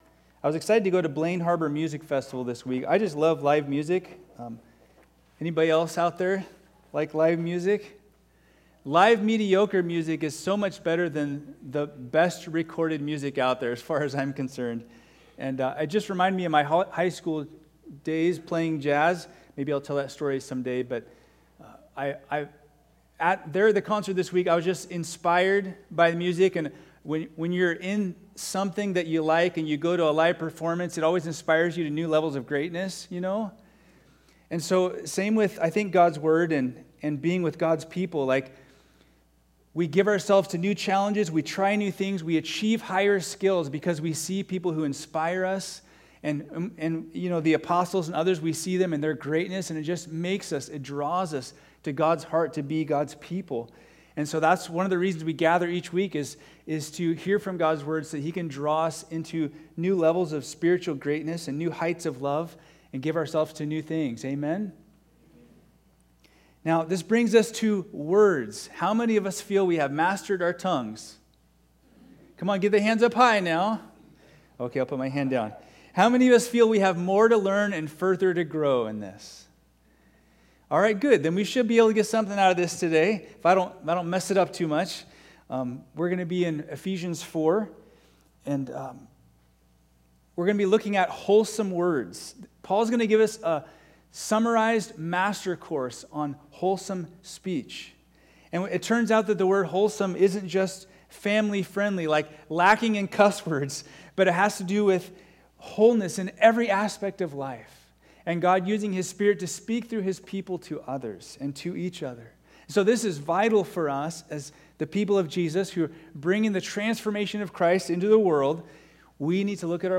All Sermons Wholesome Speech July 14